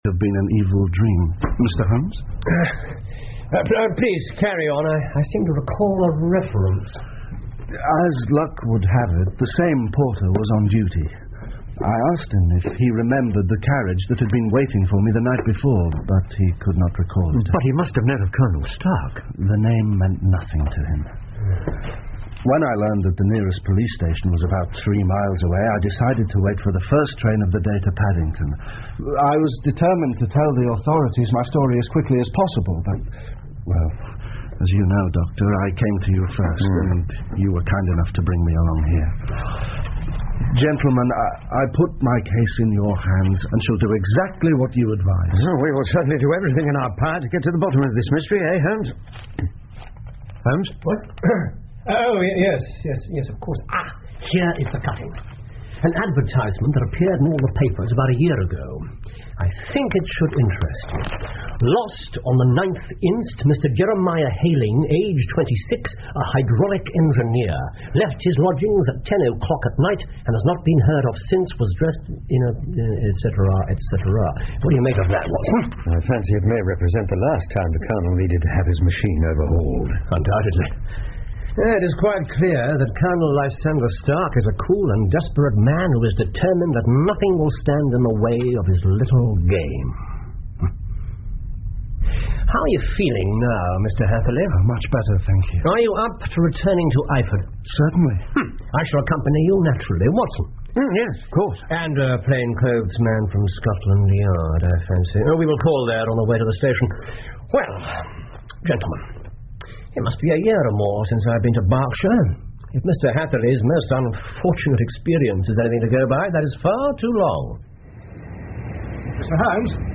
福尔摩斯广播剧 The Engineer's Thumb 7 听力文件下载—在线英语听力室